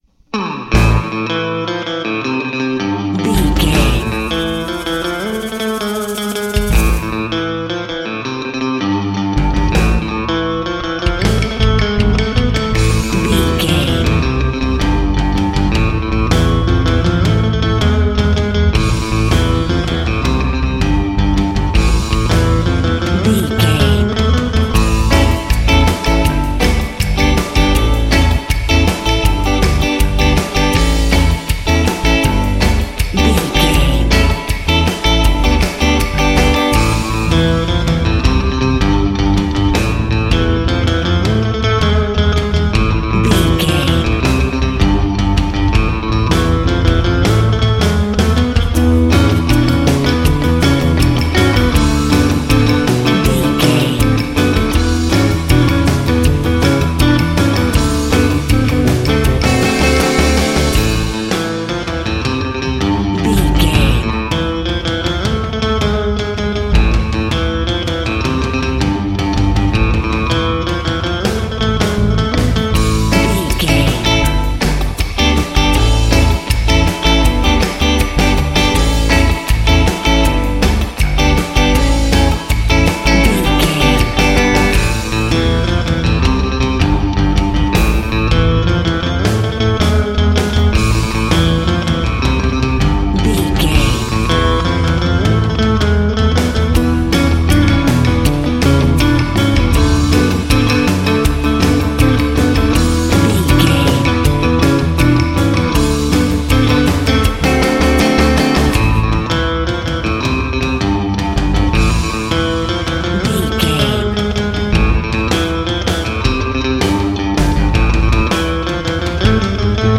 Fast paced
Aeolian/Minor
groovy
energetic
electric guitar
percussion
double bass
drums